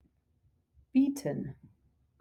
to offer bieten (BIET-en)